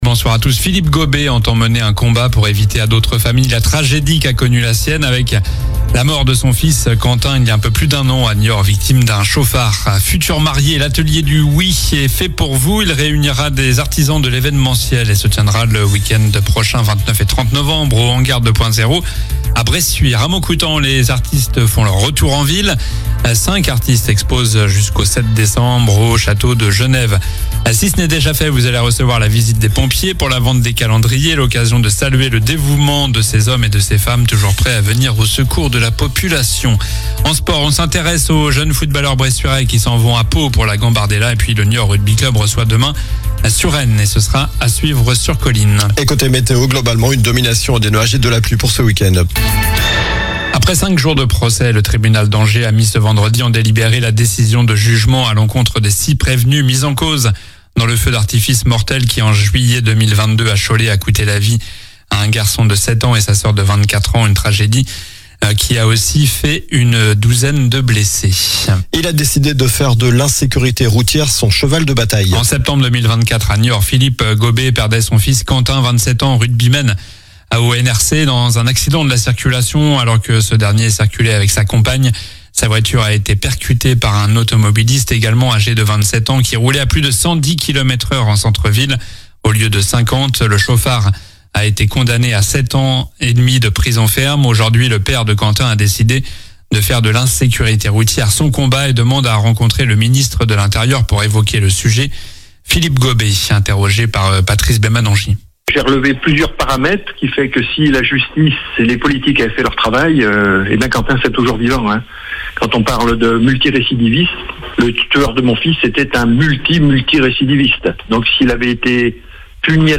Journal du vendredi 21 novembre (soir)